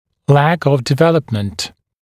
[læk əv ][лэк ов]недостаток развития, недостаточное развитие